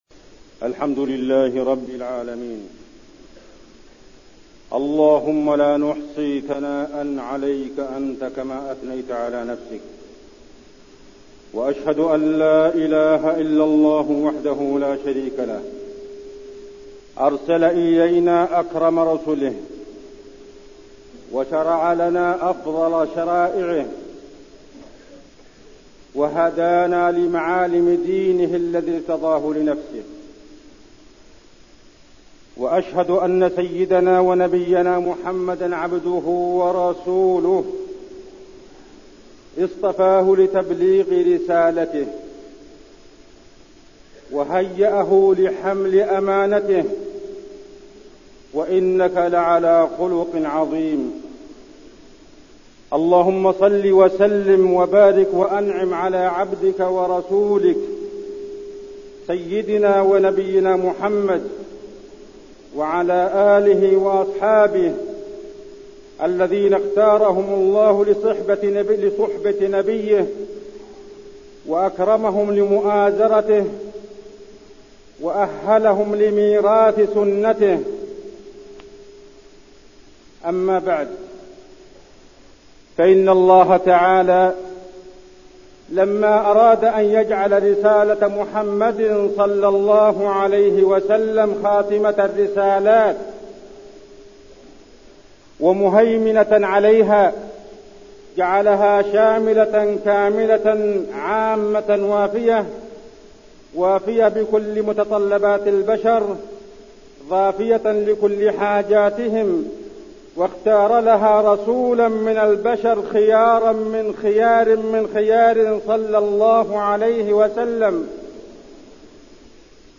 خطبة مواقف من السيرة النبوية وفيها: اصطفاء خير البشر لخير رسالة، وتربية النبي لأمته، وقصة غزوة الخندق ودروس منها
المكان: المسجد النبوي